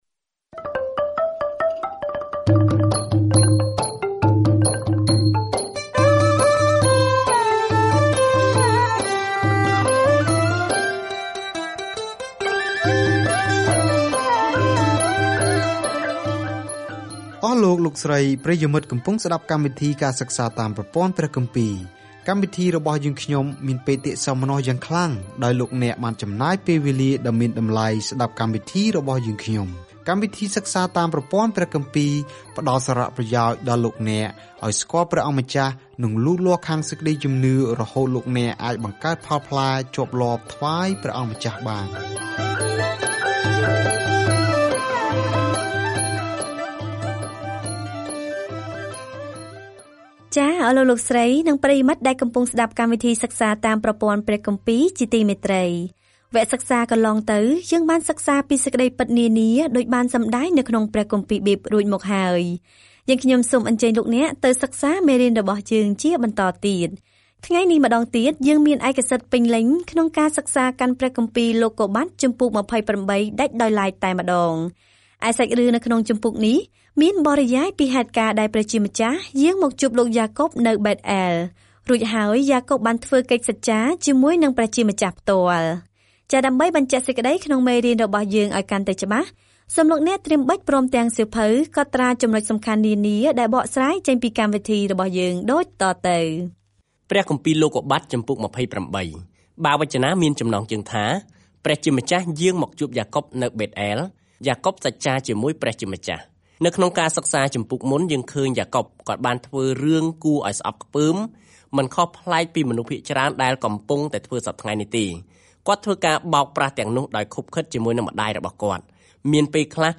ការធ្វើដំណើរប្រចាំថ្ងៃតាមរយៈលោកុប្បត្តិ នៅពេលអ្នកស្តាប់ការសិក្សាជាសំឡេង ហើយអានខគម្ពីរដែលបានជ្រើសរើសពីព្រះបន្ទូលរបស់ព្រះនៅក្នុងសៀវភៅលោកុប្បត្តិ។